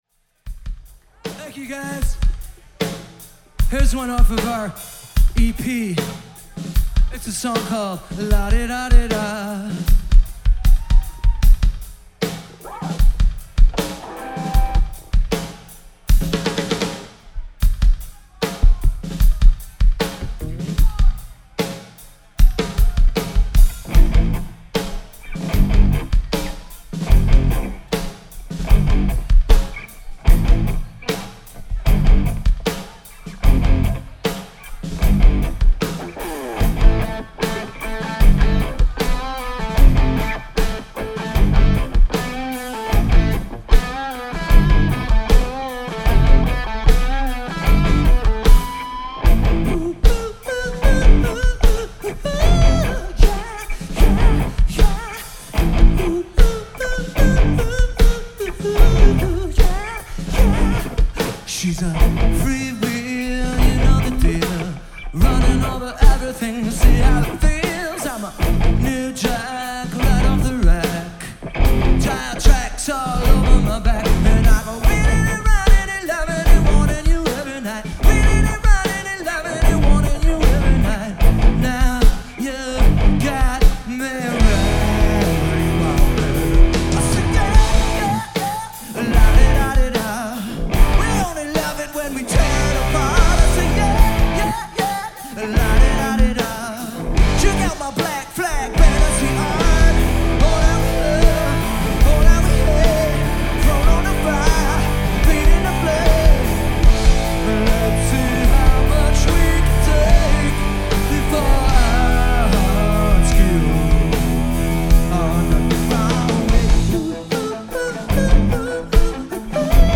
Genre: Alt.Rock.